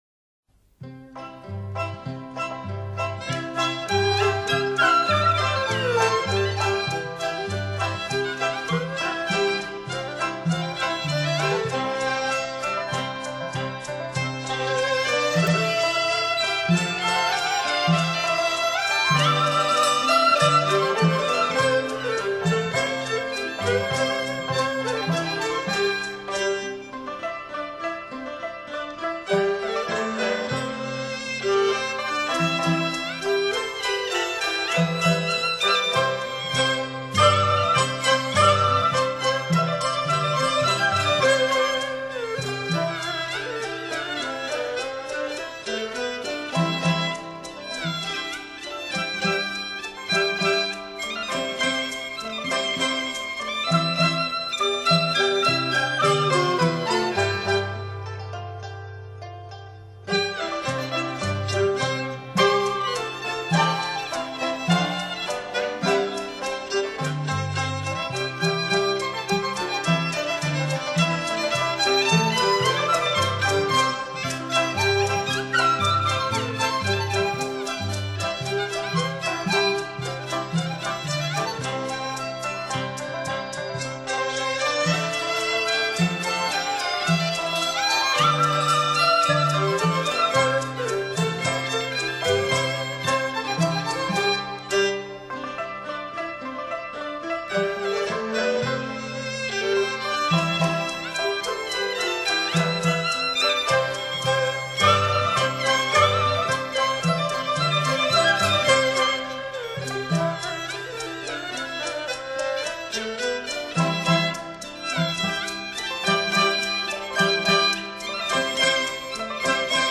广东音乐